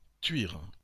Thuir (French pronunciation: [tɥiʁ]